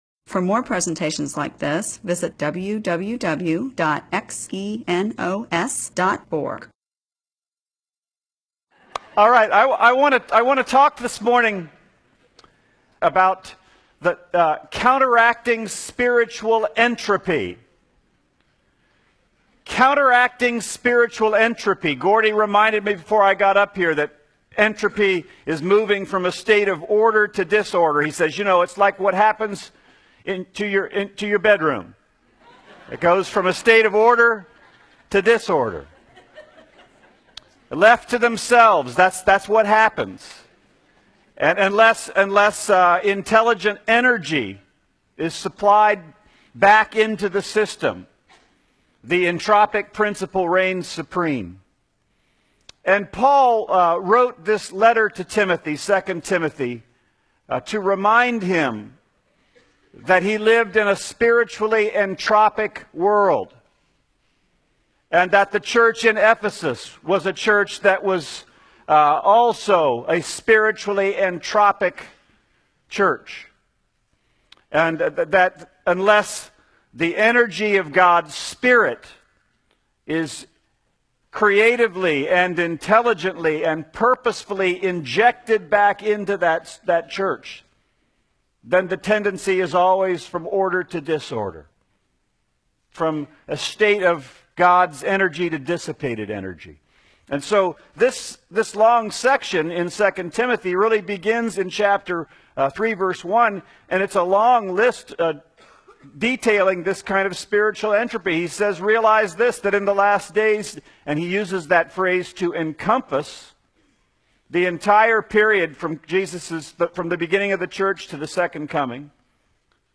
MP4/M4A audio recording of a Bible teaching/sermon/presentation about 2 Timothy 4:5.